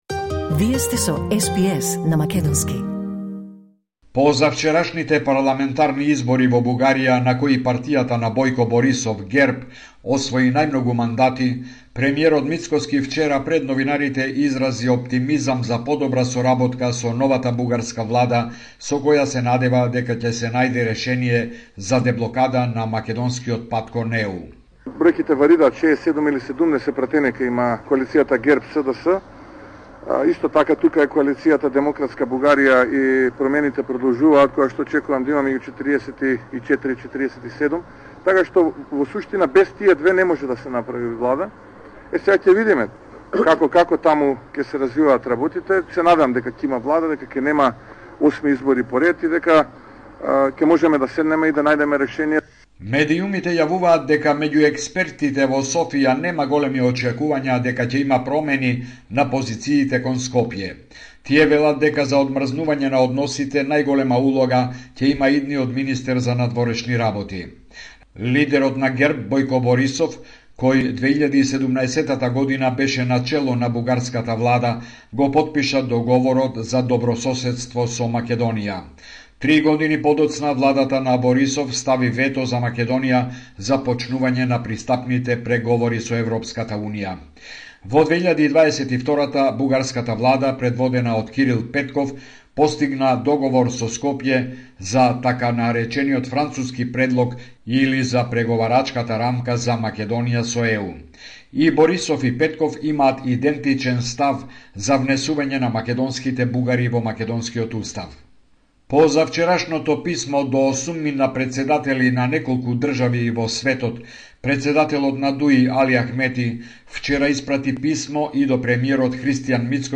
Homeland Report in Macedonian 29 October 2024